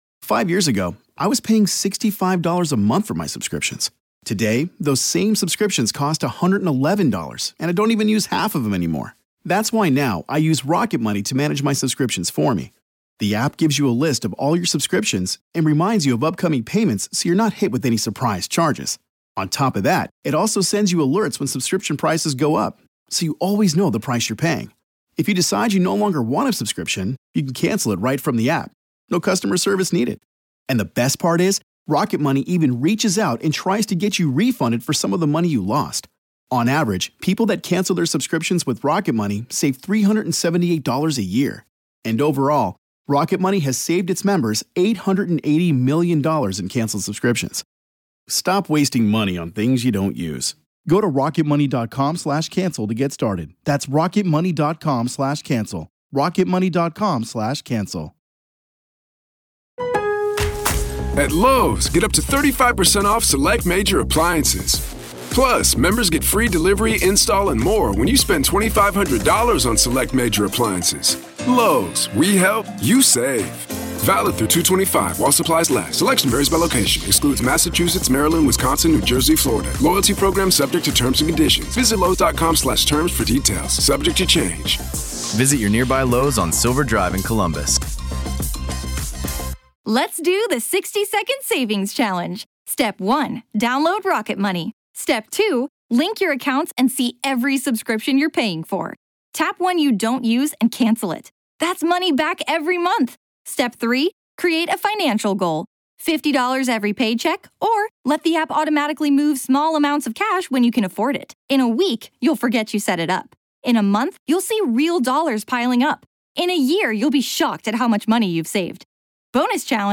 In part two of our interview we discuss: